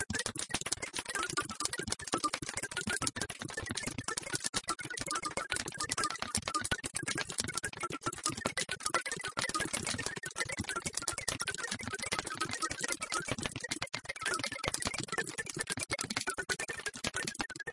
器乐层 " VARI A88
描述：提高声音混乱
标签： 扩散
声道立体声